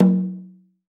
AFRO.TAMB7-S.WAV